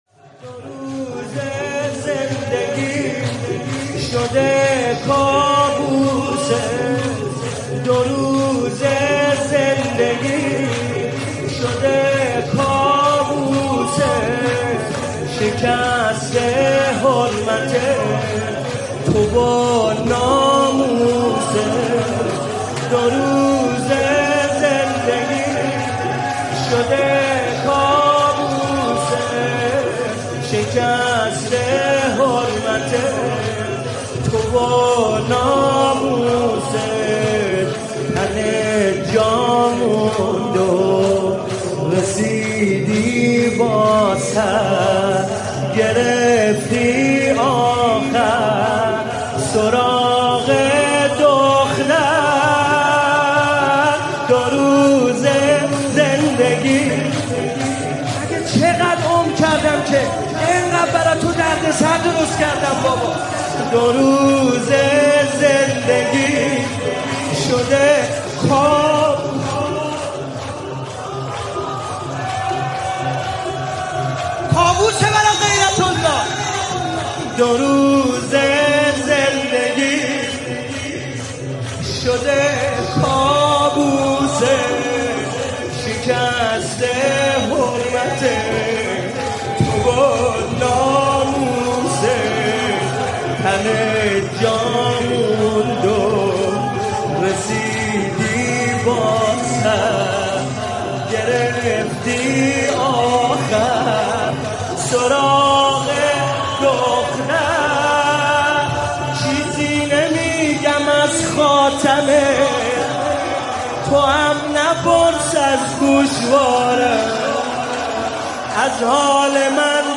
مداحی جدید کربلایی حسین طاهری شب سوم محرم97 هیئت مکتب الزهرا
دو روزه زندگیم شده کابوست - شور